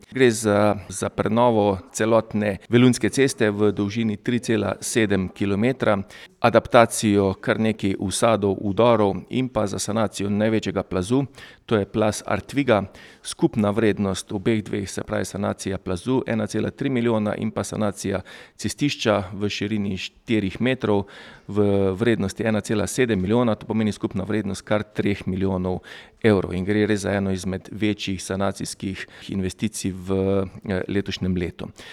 Svojemu namenu so predali eno od zahtevnejših, to je cesta na enem od najbolj prizadetih območij, od koder je bilo po naravni katastrofi celo potrebno izseliti eno družino. Župan Tilen Klugler:
IZJAVA KLUGLER 1 VELUNJA.mp3